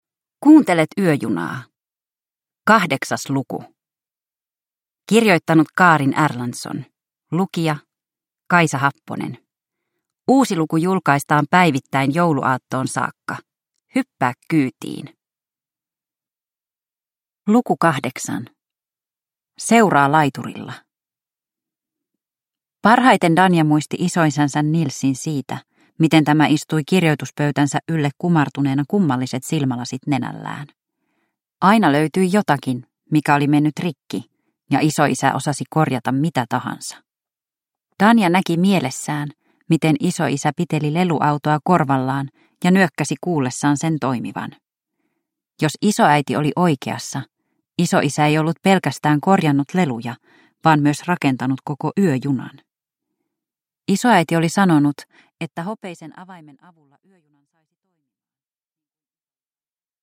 Yöjuna luku 8 – Ljudbok